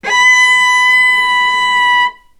healing-soundscapes/Sound Banks/HSS_OP_Pack/Strings/cello/ord/vc-B5-ff.AIF at 48f255e0b41e8171d9280be2389d1ef0a439d660
vc-B5-ff.AIF